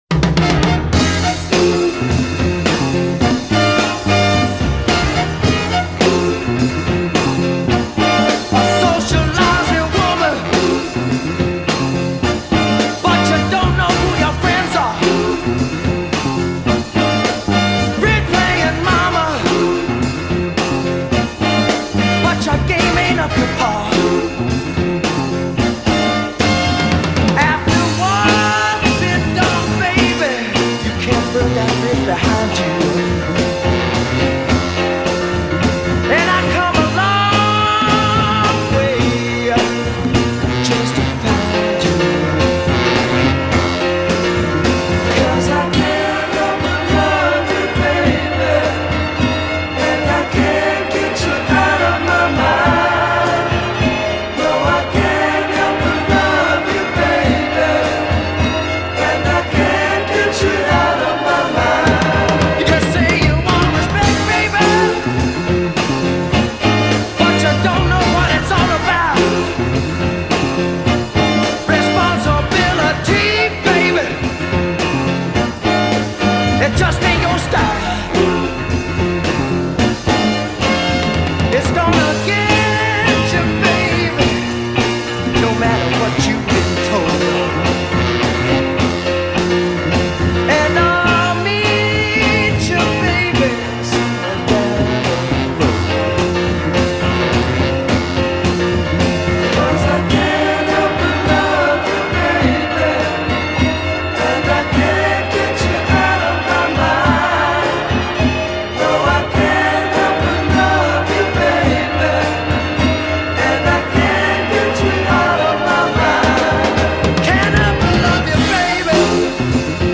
Mono Version